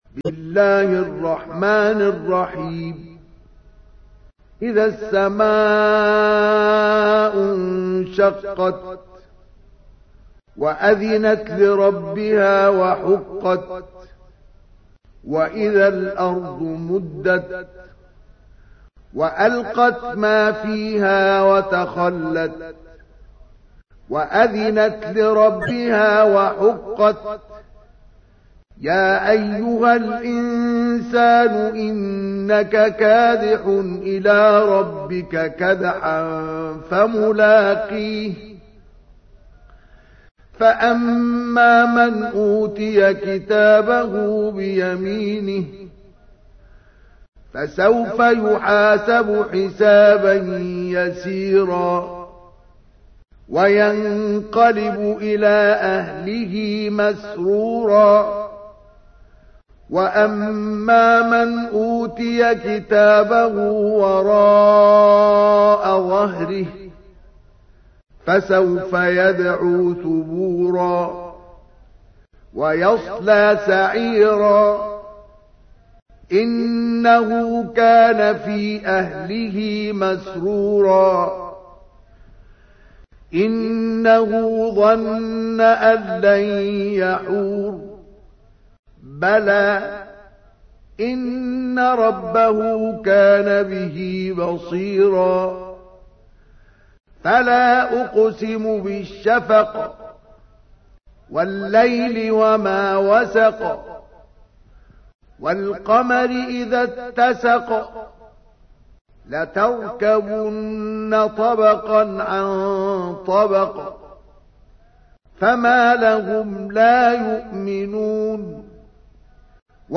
تحميل : 84. سورة الانشقاق / القارئ مصطفى اسماعيل / القرآن الكريم / موقع يا حسين